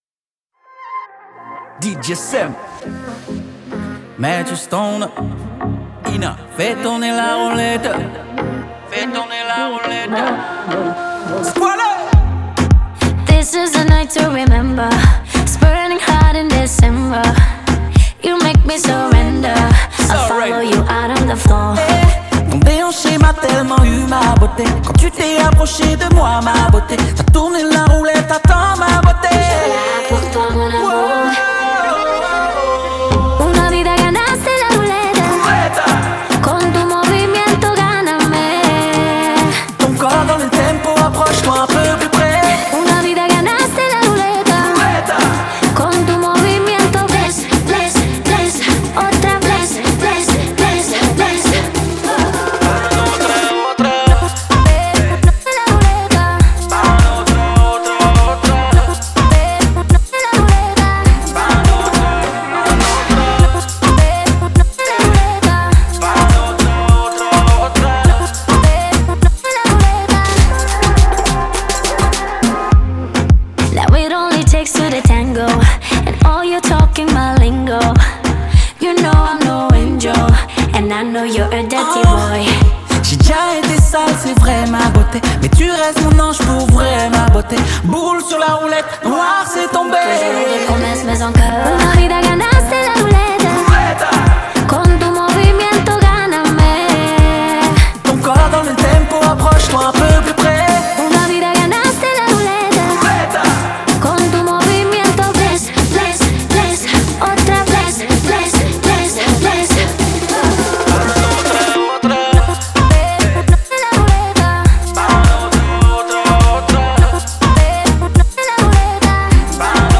это зажигательный трек в жанре евро-поп и dance